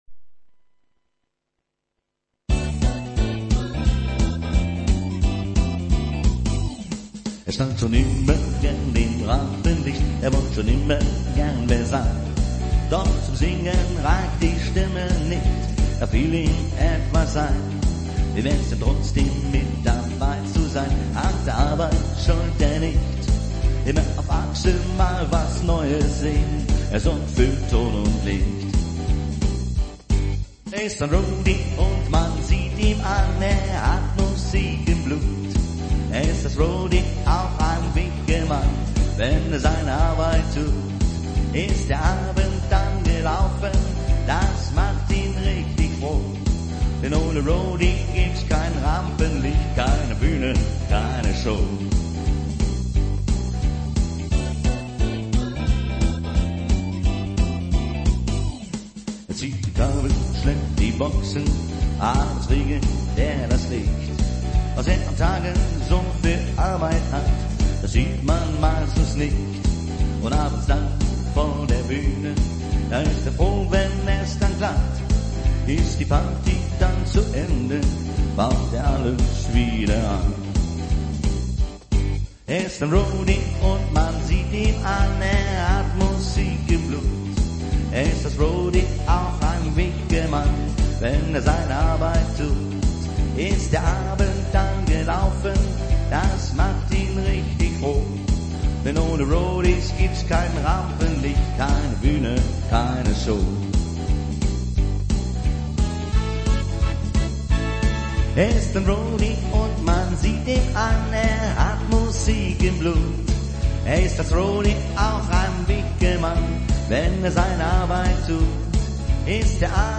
Rock´n Roll